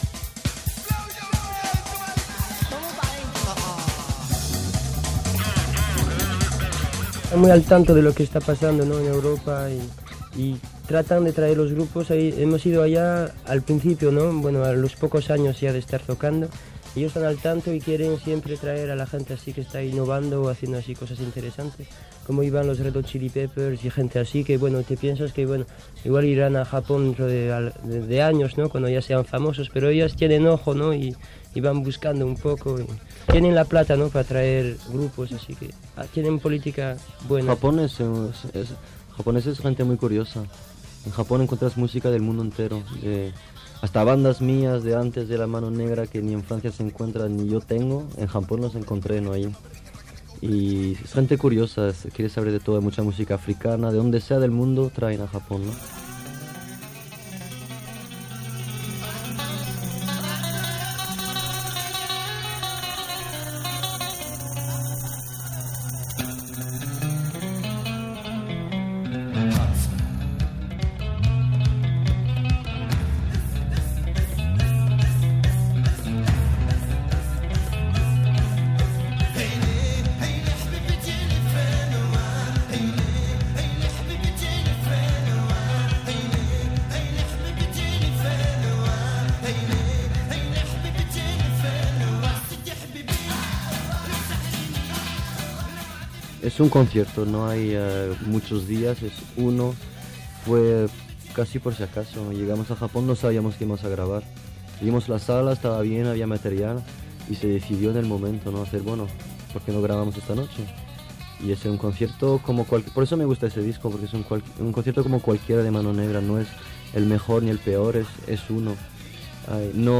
Roda de premsa del grup Mano Negra, amb Manu Chao, que actuaven a la sala Zeleste el 23 de febrer de 1991
Fragment extret del programa "Com sonava" emès el 26 de novembre de 2016 per Ràdio 4.